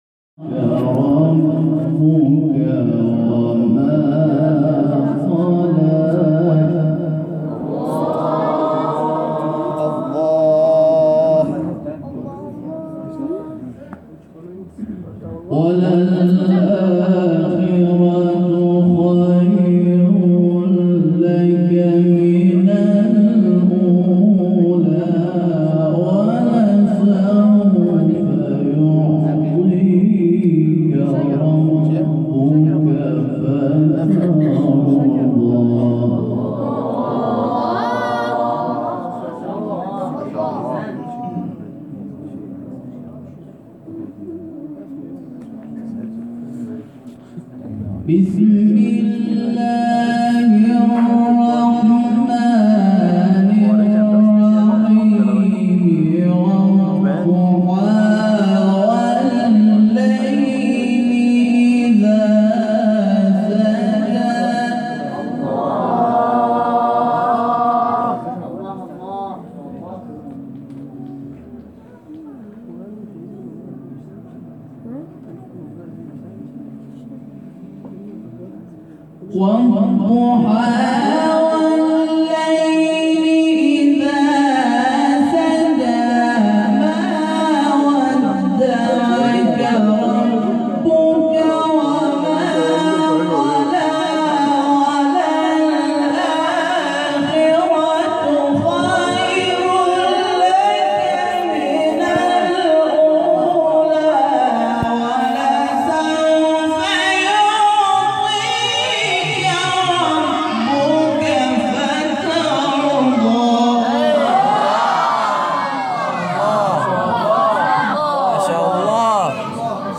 فایل صوتی از تلاوت سوره ضحی و شرح که در کانال تلگرامی جامعه قرآنی کشور منتشر شده است، می‌شنویم.